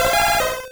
Cri d'Osselait dans Pokémon Rouge et Bleu.